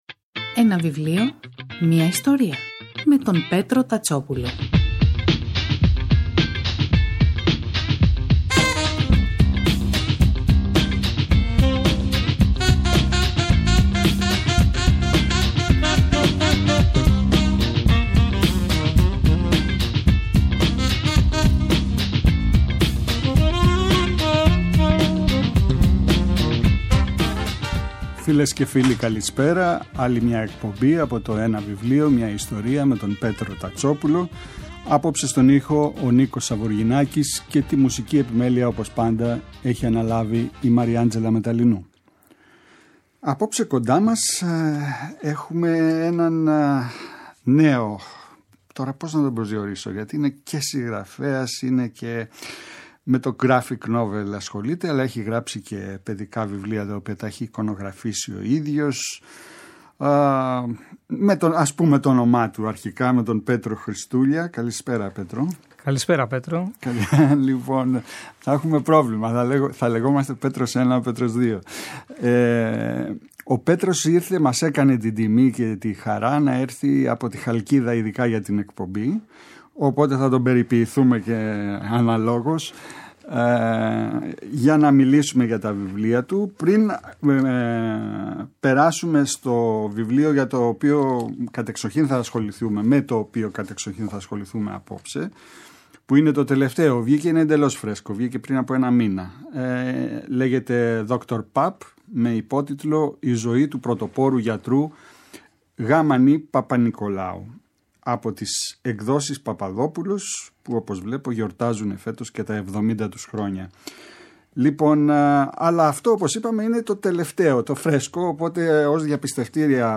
καλεσμένος στην εκπομπή
Η εκπομπή “Ένα βιβλίο, μια ιστορία” του Πέτρου Τατσόπουλου, κάθε Σάββατο και Κυριακή, στις 5 το απόγευμα στο Πρώτο Πρόγραμμα της Ελληνικής Ραδιοφωνίας παρουσιάζει ένα συγγραφικό έργο, με έμφαση στην τρέχουσα εκδοτική παραγωγή, αλλά και παλαιότερες εκδόσεις.